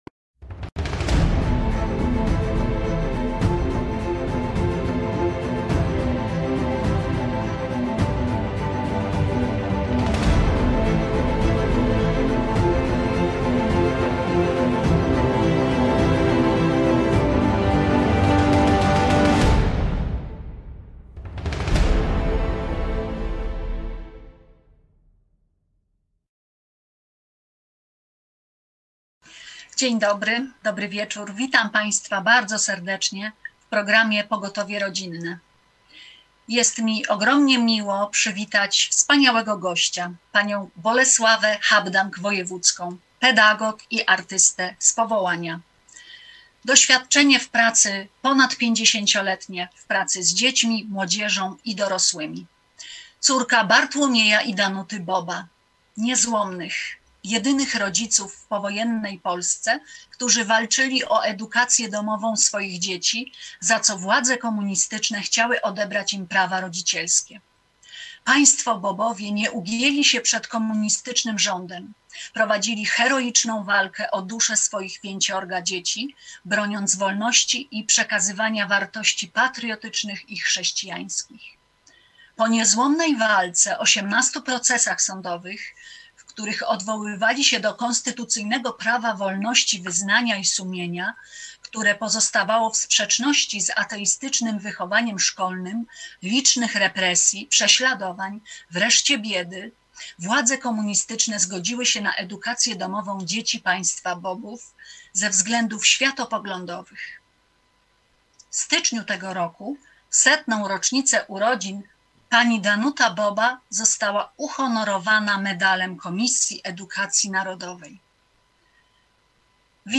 Komuniści chcieli im za to odebrać prawa rodzicielskie. Wywiad